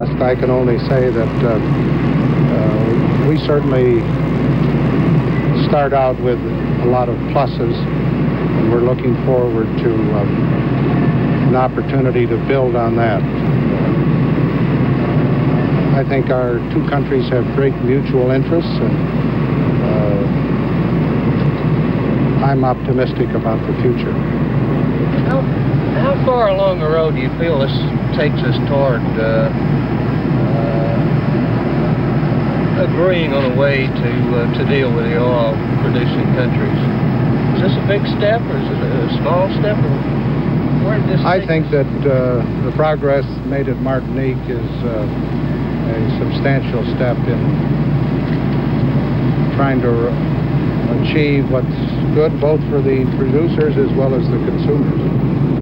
President Gerald Ford speaks about the international oil crisis upon returning from his Martinique meetings with President Giscard d'Estaing.
Broadcast on NBC-TV, December 17, 1974.